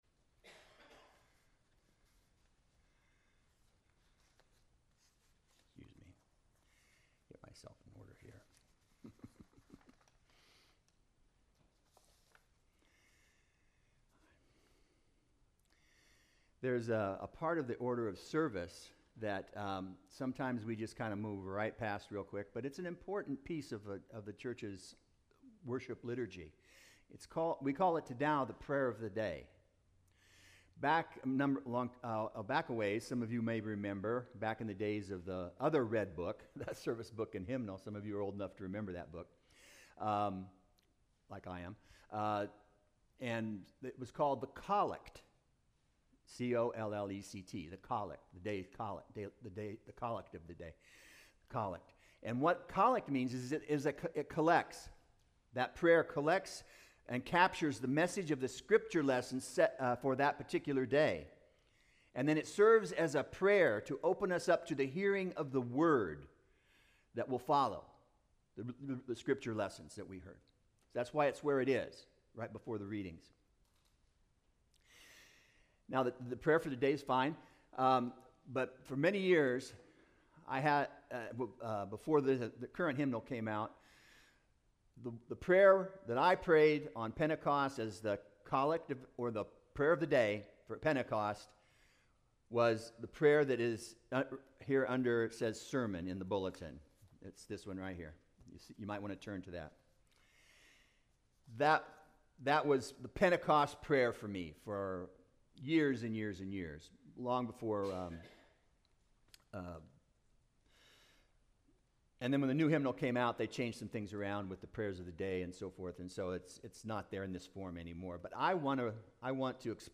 Sermon 06.08.25